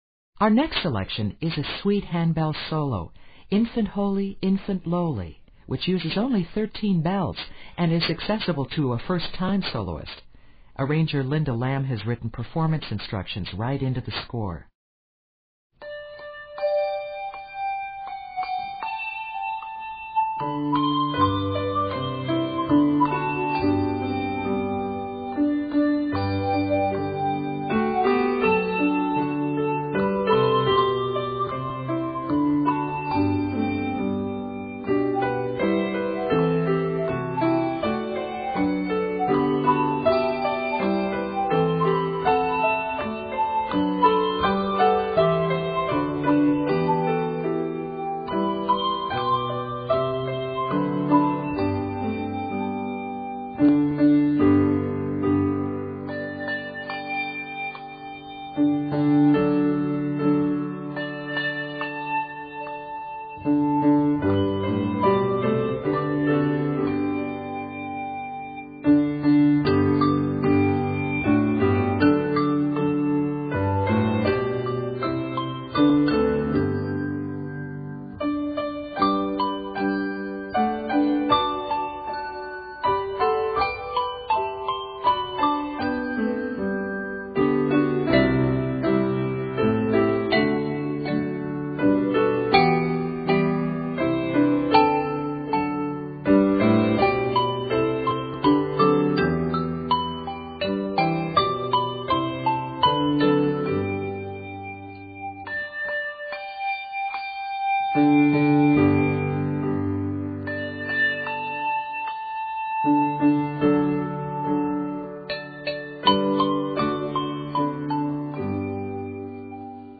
There's even a nice malleted section for contrast.